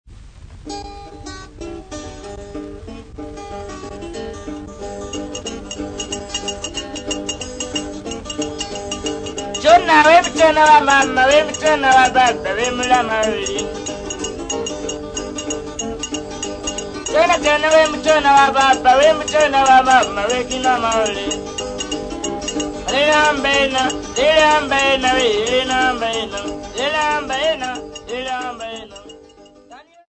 Popular music--Africa
Field recordings
sound recording-musical
A song accompanied by guitar and bottle